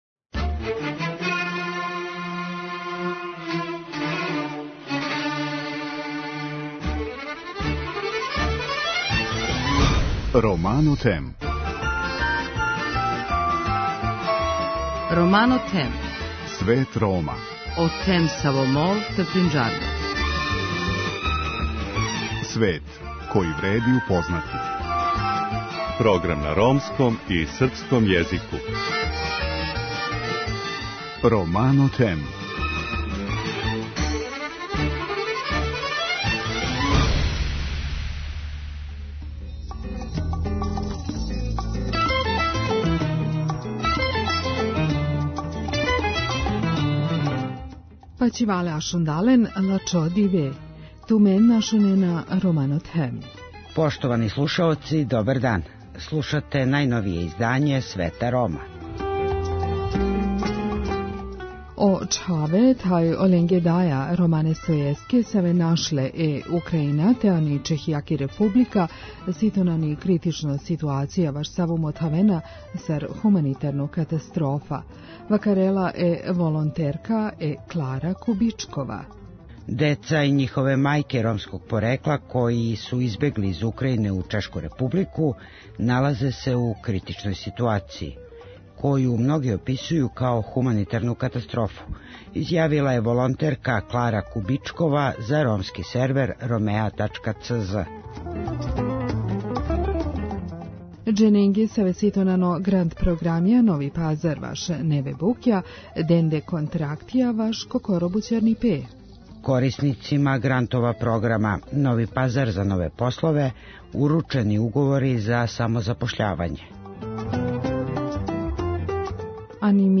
Вести на ромском језику